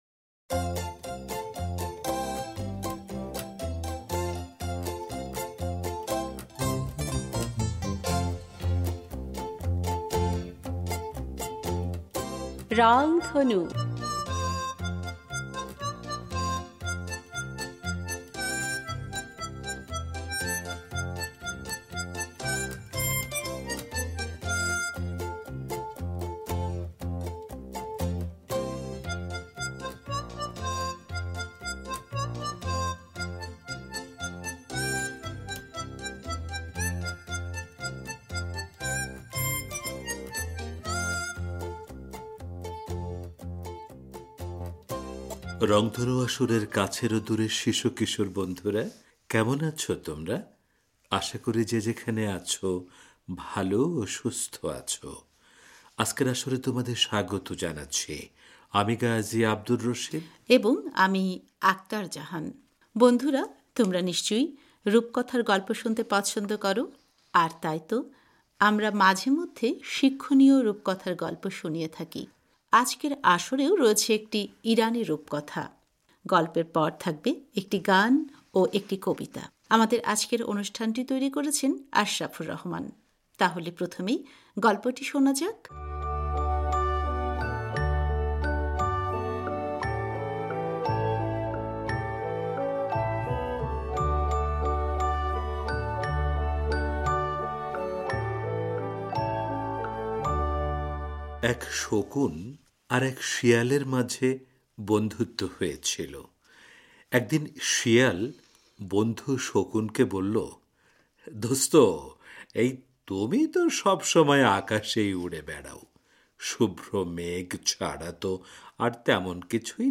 আজকের আসরেও রয়েছে একটি ইরানি রূপকথা। গল্পের পর থাকবে একটি গান ও একটি কবিতা।